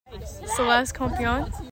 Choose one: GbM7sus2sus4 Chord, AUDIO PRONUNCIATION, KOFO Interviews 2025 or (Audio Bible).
AUDIO PRONUNCIATION